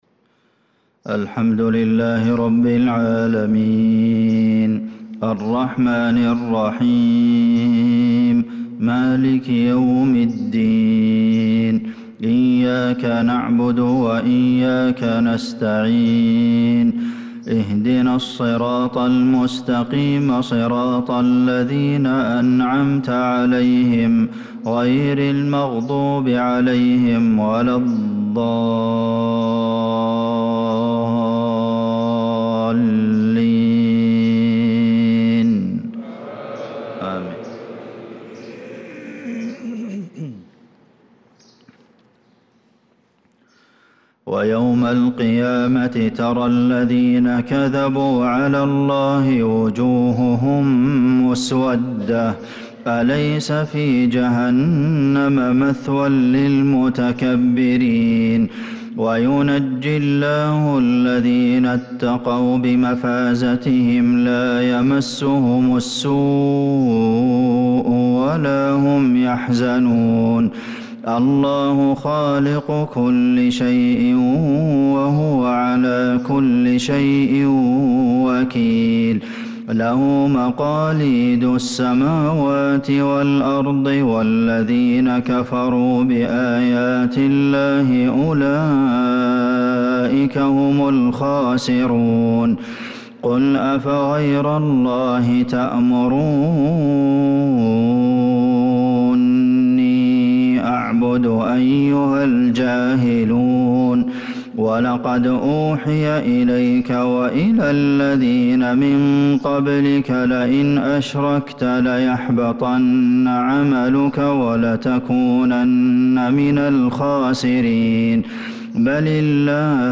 صلاة الفجر للقارئ عبدالمحسن القاسم 4 صفر 1446 هـ
تِلَاوَات الْحَرَمَيْن .